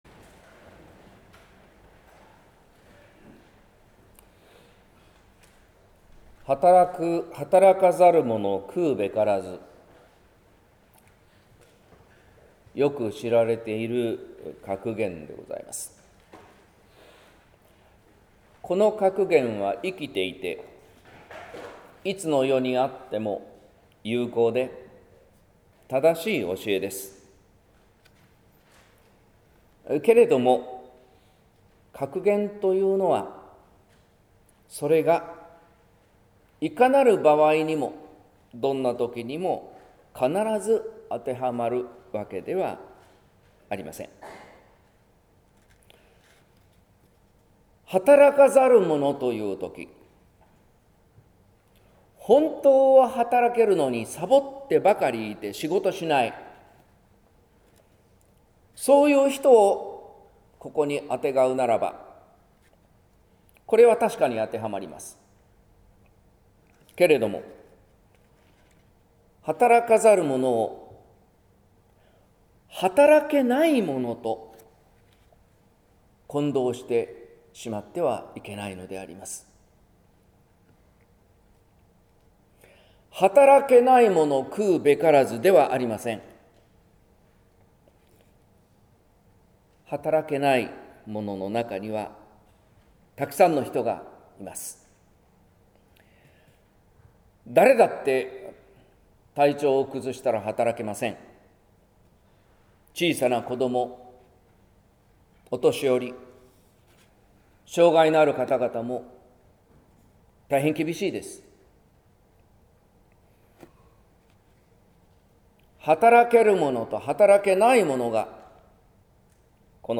説教「できる人、できない人」（音声版） | 日本福音ルーテル市ヶ谷教会